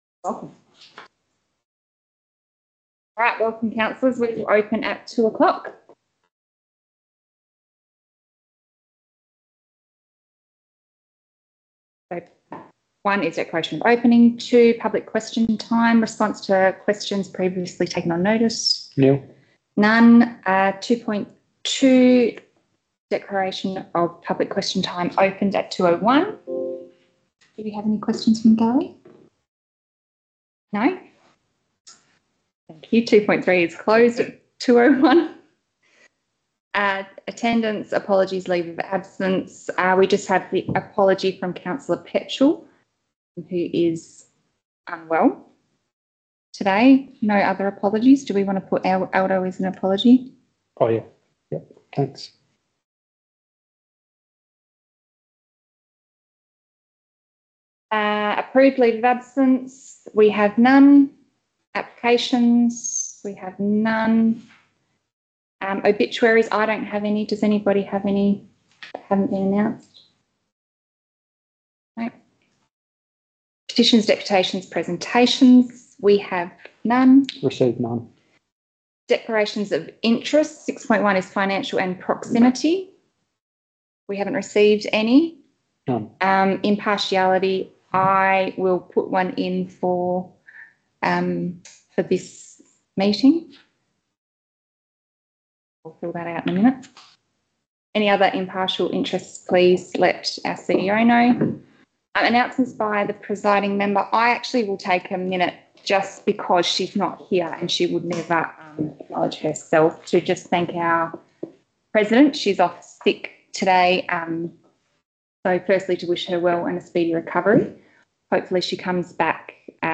Special Council Meeting » Shire of Wyalkatchem
Location: Shire of Wyalkatchem Council Chambers